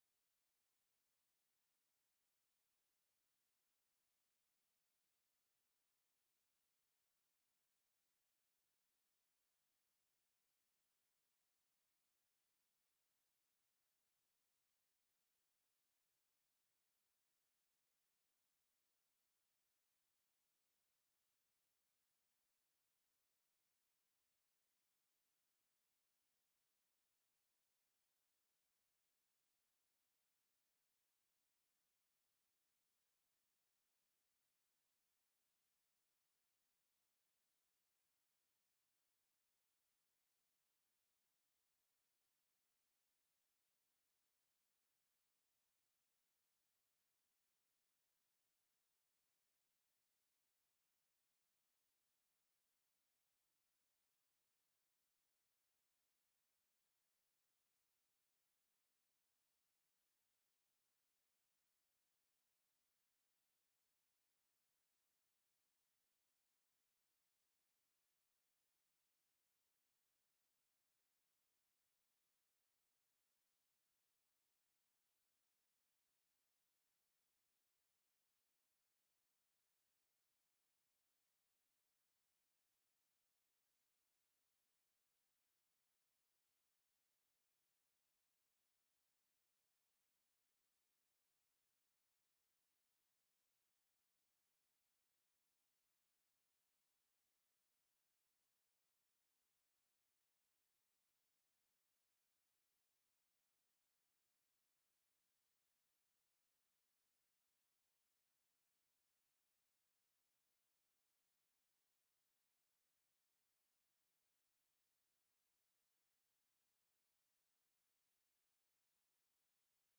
06-25-23 Sunday Worship
Our talented worship team brings the A game this week.